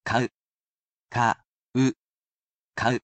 I am sure to read each of the words aloud for you, slowly and carefully.